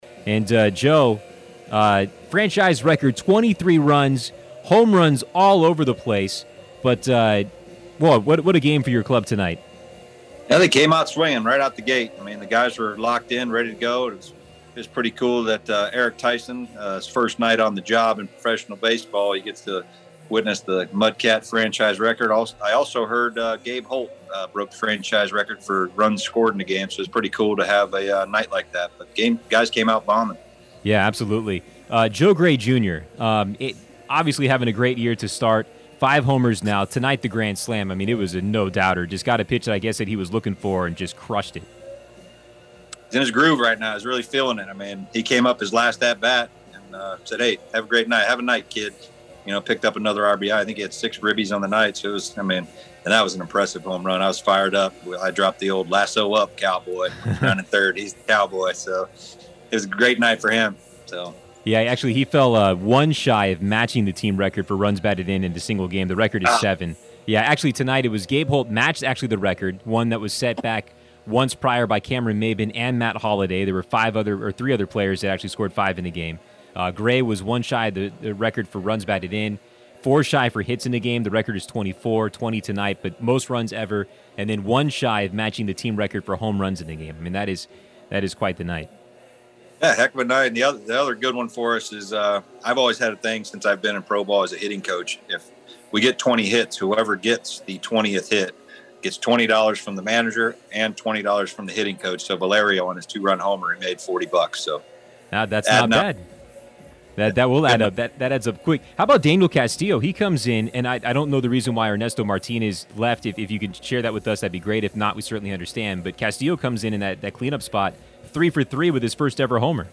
Post-Game Interview